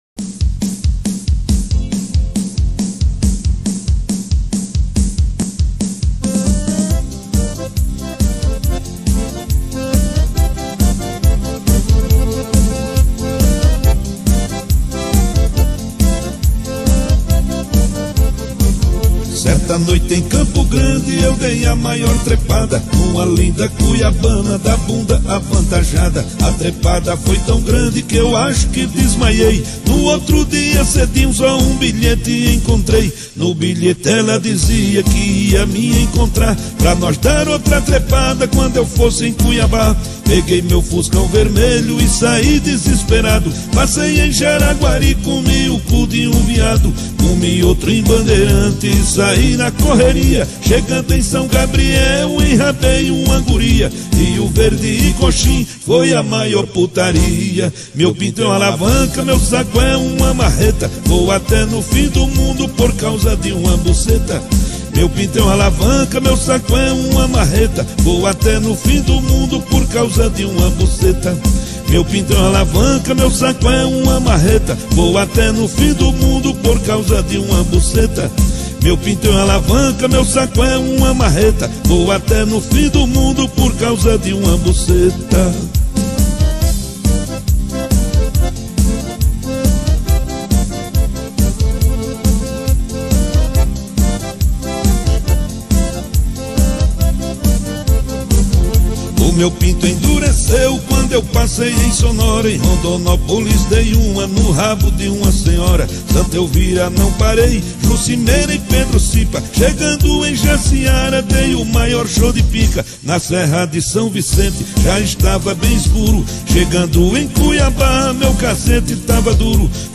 2024-06-26 23:19:03 Gênero: Sertanejo Views